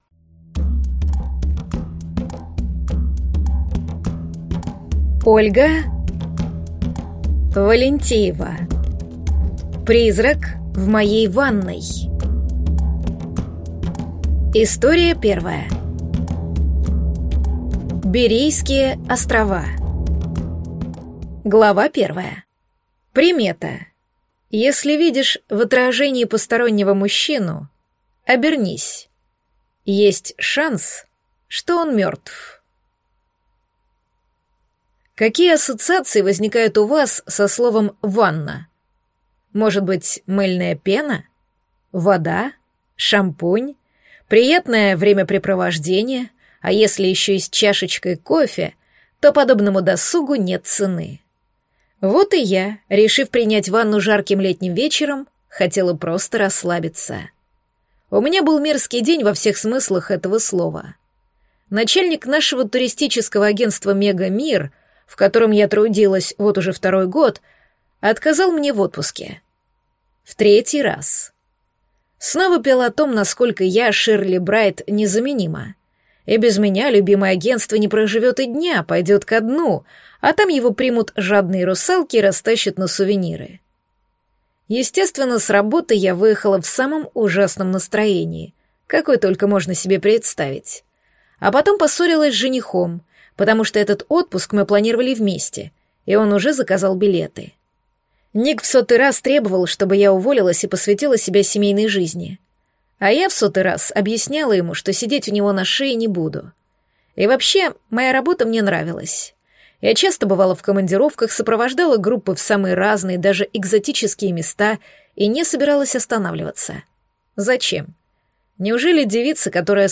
Призрак в моей ванной (слушать аудиокнигу бесплатно) - автор Ольга Валентеева